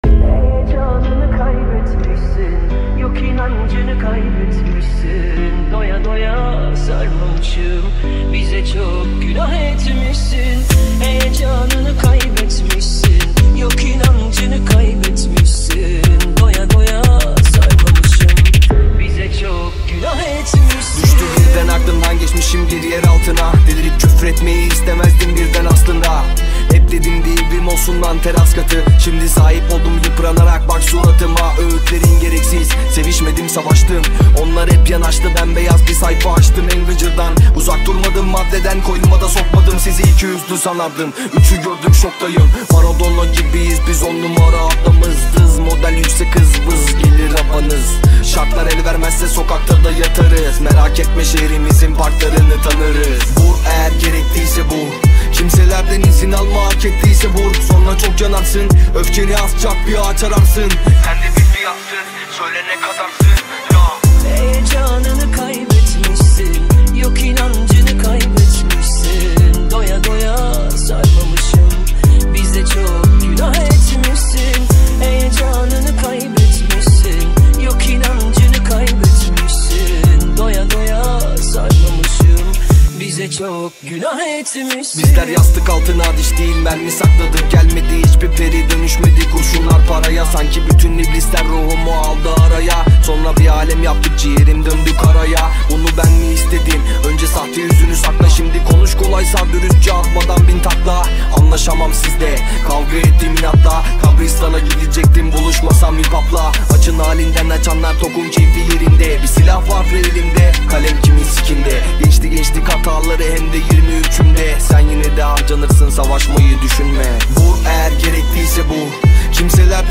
• Жанр: Узбекские песни
Tiktok Mix